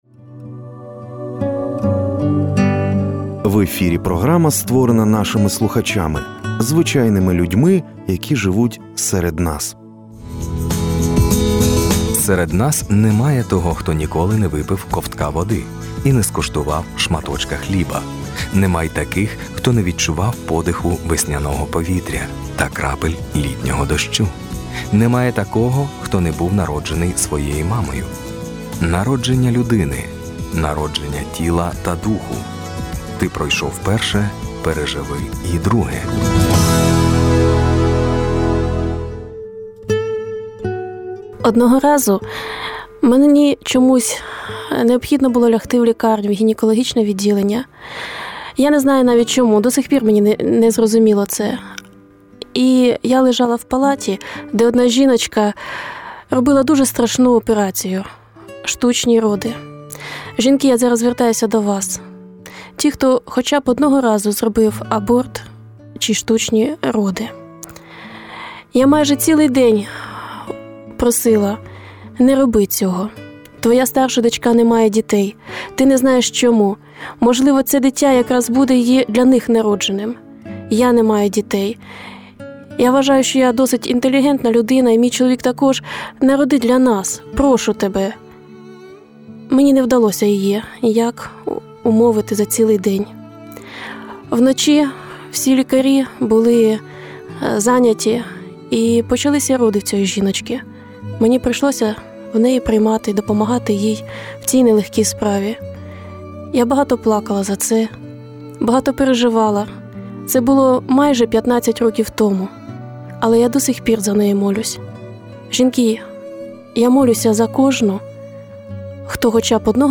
Молода дівчина ділиться своїми сильними спогадами з лікарні, де вона стала свідком штучних пологів. Ця подія глибоко вразила її, і до сьогодні вона не перестає молитися за ту жінку та за всіх, хто розглядає можливість переривання вагітності,...